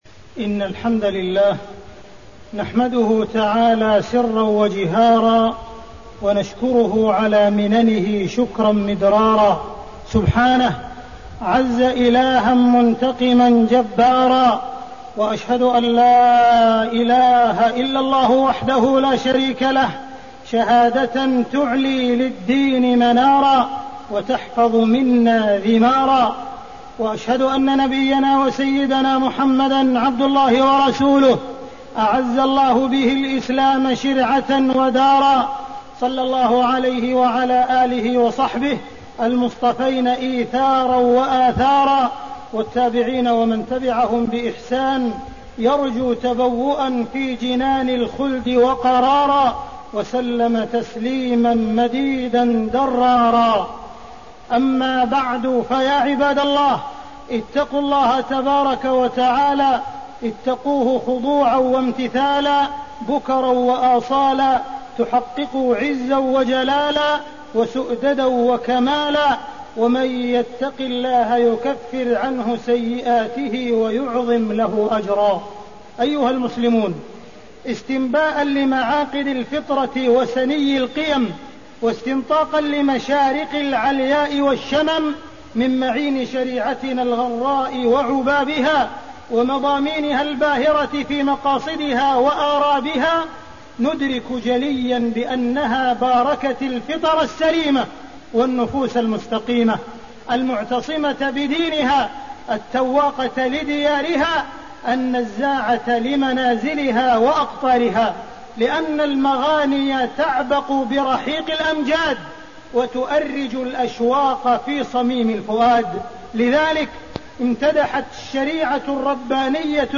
تاريخ النشر ٢٨ صفر ١٤٣١ هـ المكان: المسجد الحرام الشيخ: معالي الشيخ أ.د. عبدالرحمن بن عبدالعزيز السديس معالي الشيخ أ.د. عبدالرحمن بن عبدالعزيز السديس الدفاع عن الأوطان الإسلامية